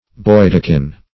Boydekin \Boy"de*kin\, n. A dagger; a bodkin.